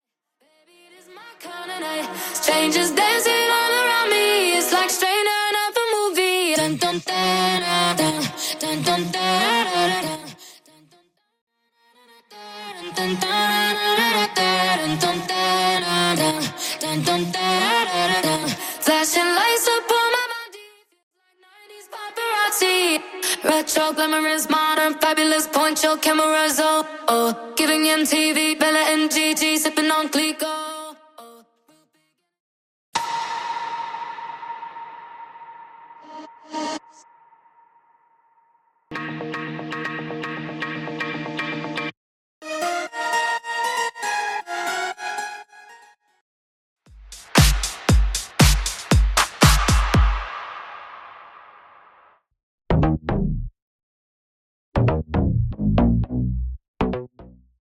Studio Bassline & 808 Stem
Studio Pads & Circle Edits Stem
Studio Percussion & Drums Stem
Studio Strings, FX & Synths Stem